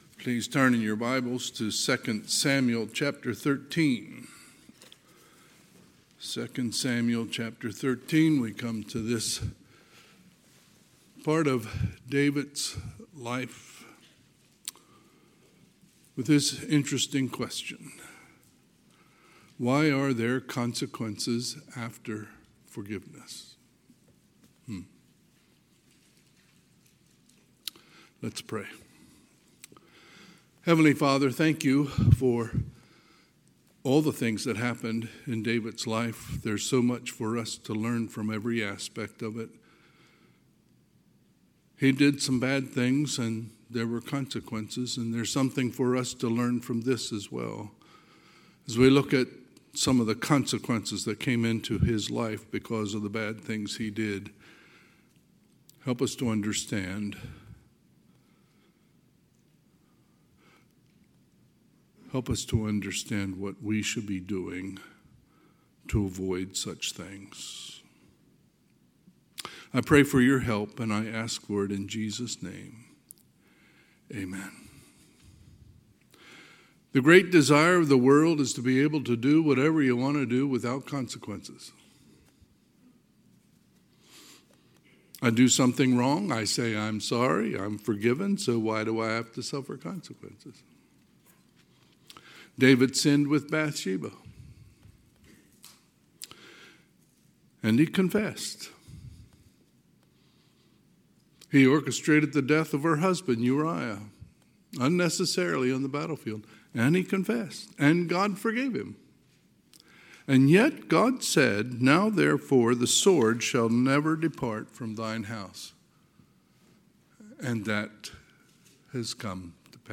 Sunday, August 31, 2025 – Sunday AM
Sermons